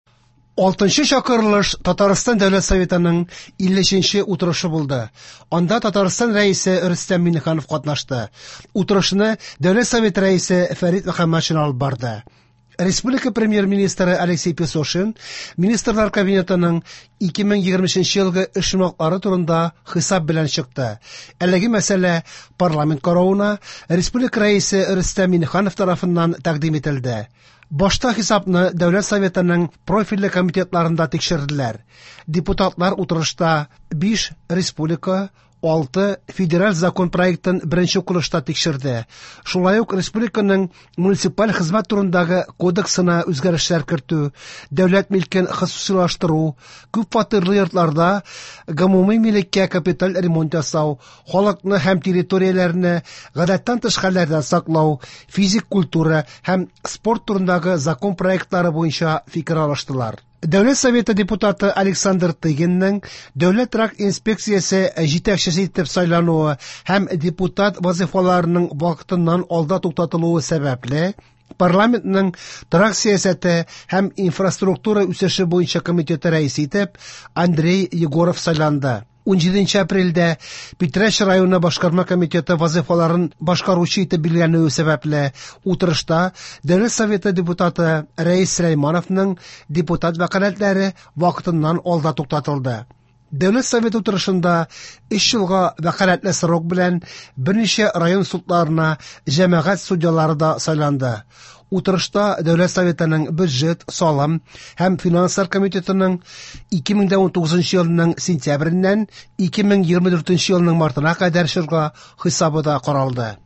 Радиоотчет (19.04.24)
В эфире специальный информационный выпуск, посвященный 53 заседанию Государственного Совета Республики Татарстан 6-го созыва.